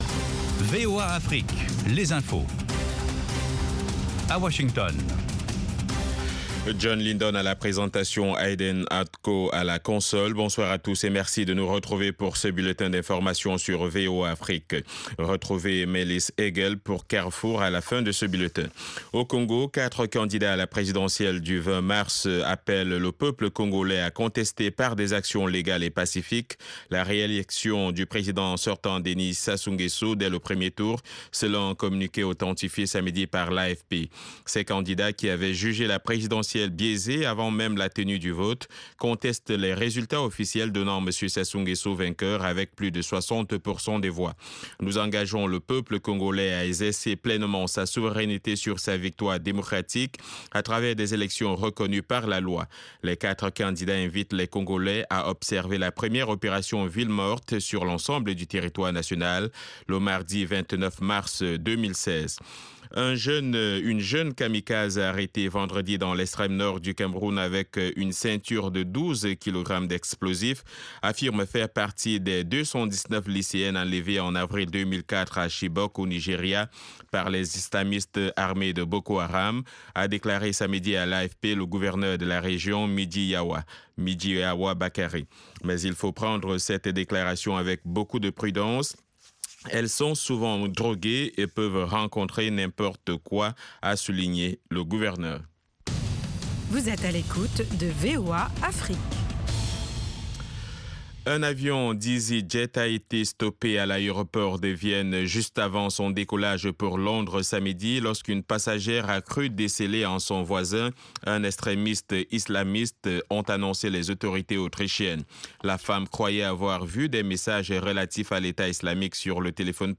Bulletin
5 Min Newscast